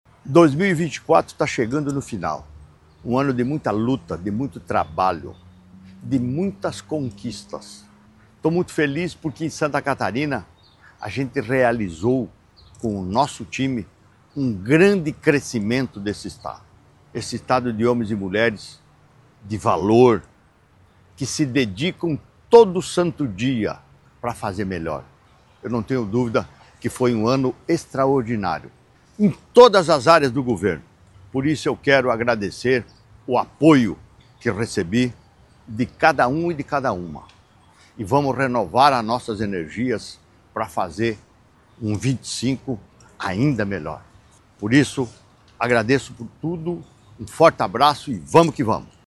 O governador Jorginho Mello deixou sua mensagem de fim de ano aos catarinenses e a quem escolheu esta terra para viver.